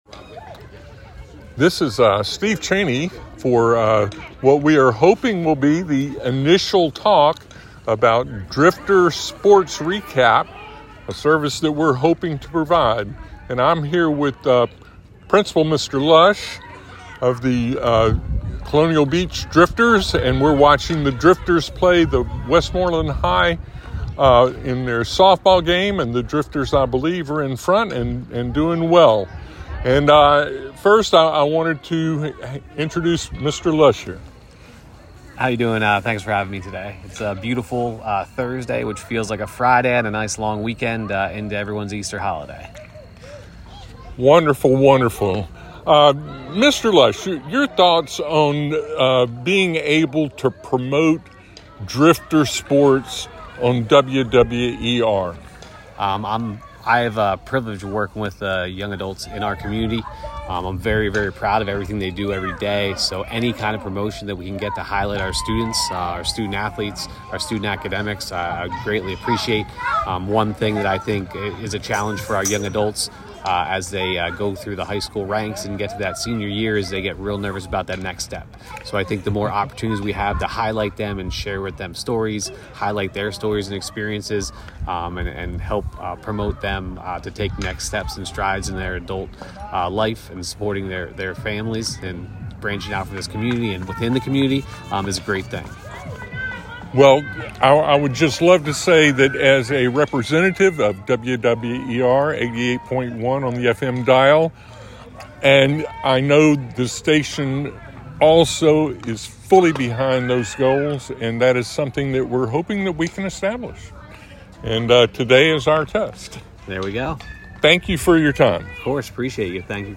The audio recording captures their initial discussion during a Drifters softball game against Westmoreland High.